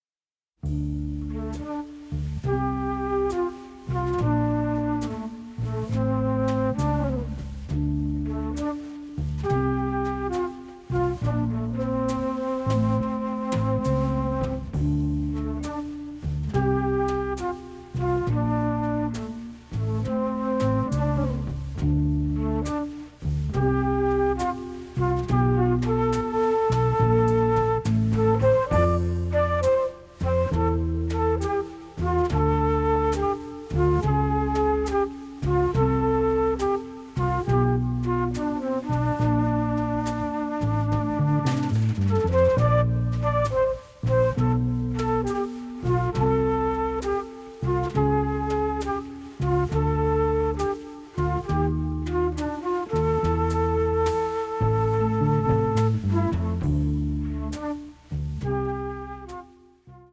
soft swing